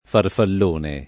farfallone
[ farfall 1 ne ]